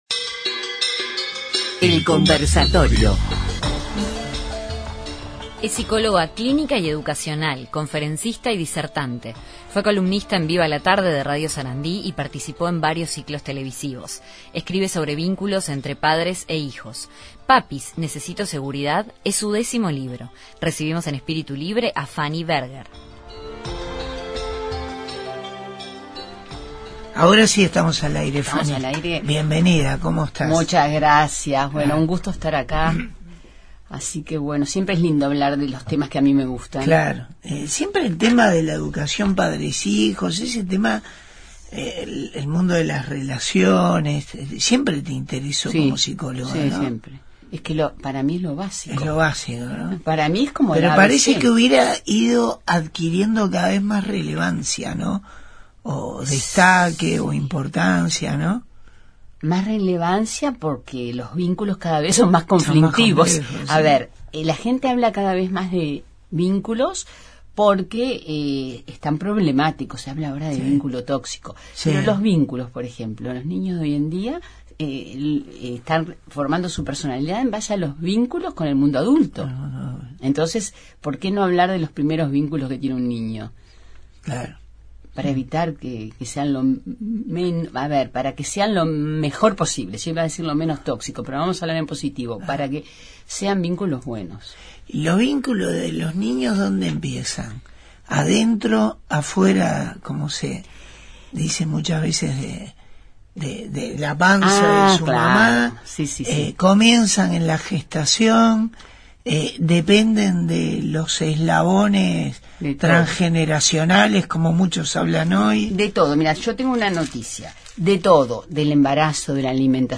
Conversamos con la psicóloga